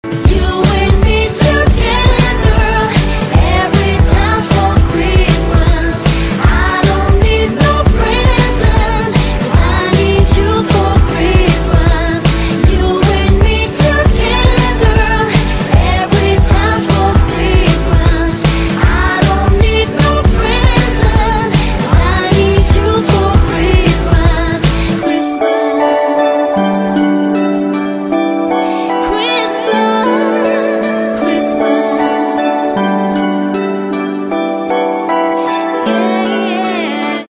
Рождественская песня